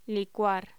Título Locución: Licuar